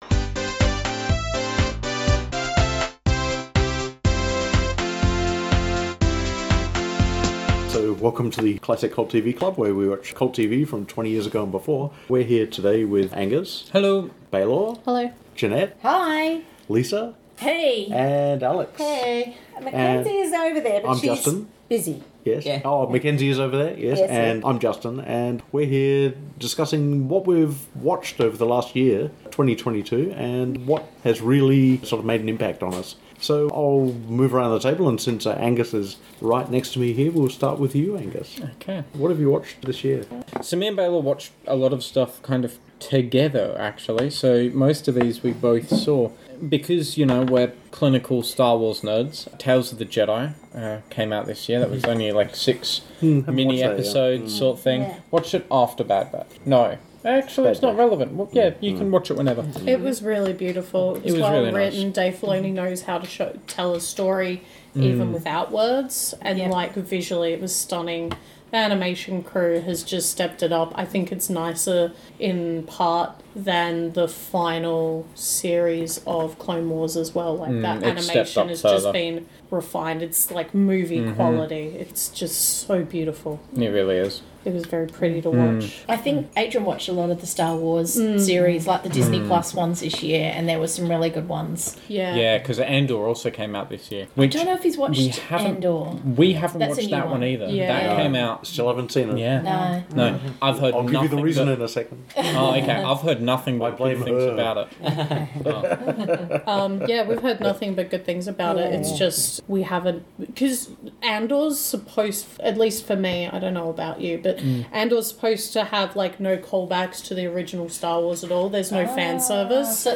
(This recording originally went for almost an hour. Some editing was required..)
Opening and closing music